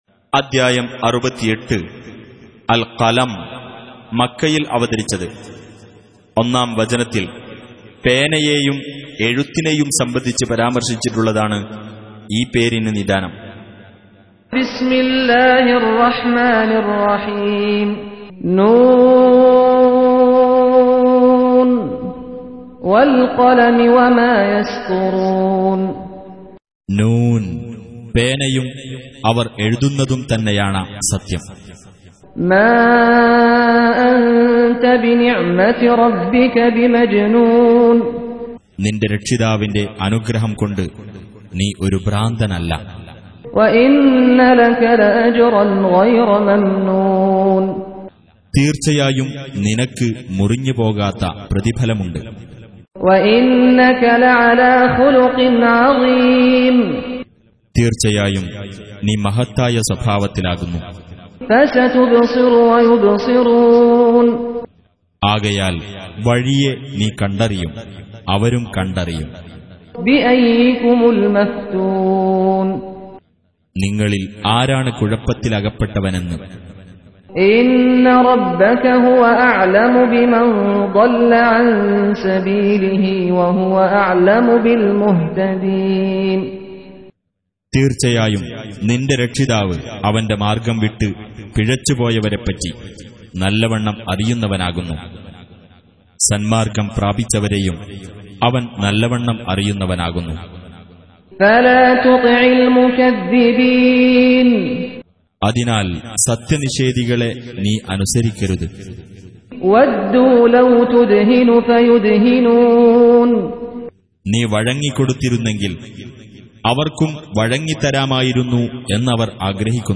Surah Repeating تكرار السورة Download Surah حمّل السورة Reciting Mutarjamah Translation Audio for 68. Surah Al-Qalam سورة القلم N.B *Surah Includes Al-Basmalah Reciters Sequents تتابع التلاوات Reciters Repeats تكرار التلاوات